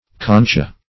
Concha \Con"cha\ (k[o^][ng]"k[.a]), n. [LL. (in sense 1), fr. L.